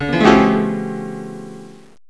1 channel
piano2.wav